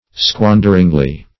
\Squan"der*ing*ly\